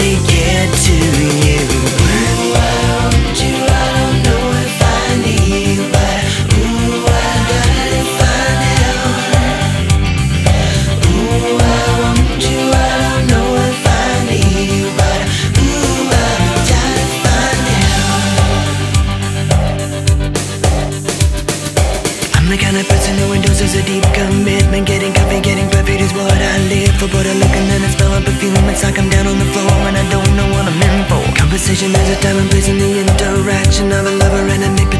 Pop Rock Adult Alternative Adult Contemporary Dance
Жанр: Поп музыка / Рок / Танцевальные / Альтернатива